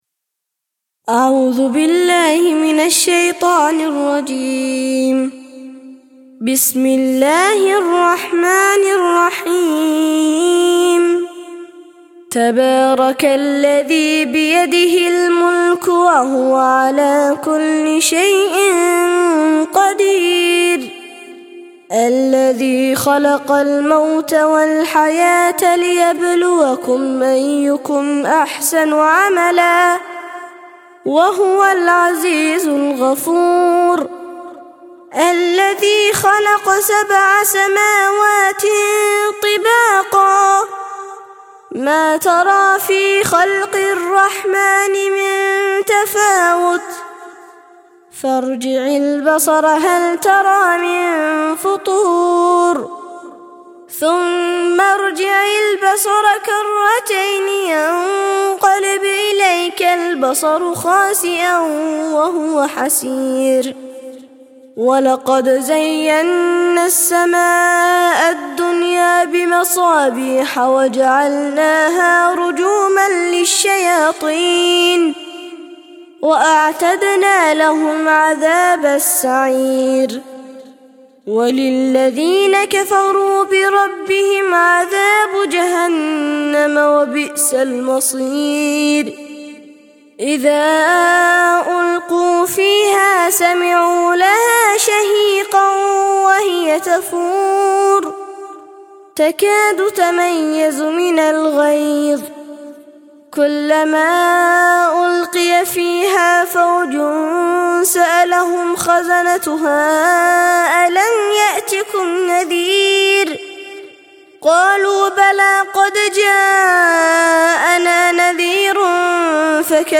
67- سورة الملك - ترتيل سورة الملك للأطفال لحفظ الملف في مجلد خاص اضغط بالزر الأيمن هنا ثم اختر (حفظ الهدف باسم - Save Target As) واختر المكان المناسب